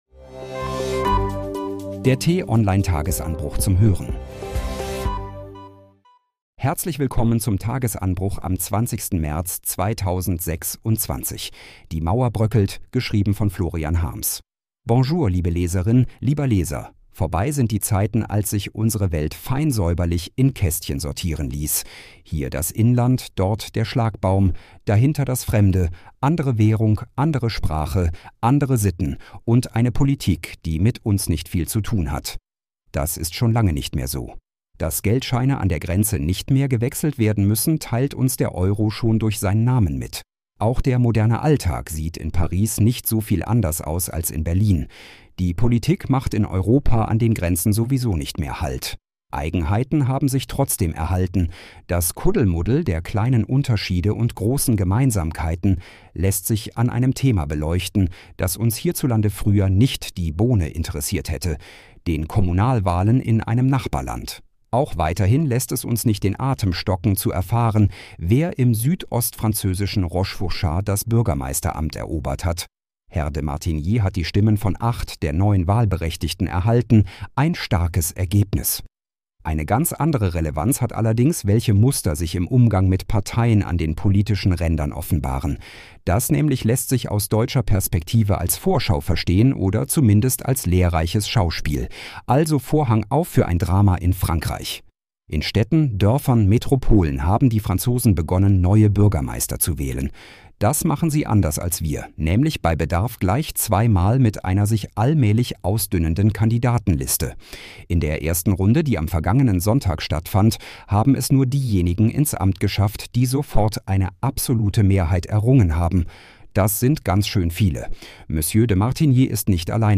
Den „Tagesanbruch“-Podcast gibt es immer montags bis freitags ab 6 Uhr zum Start in den Tag vorgelesen von einer freundlichen KI-Stimme – am Wochenende mit einer tiefgründigeren Diskussion.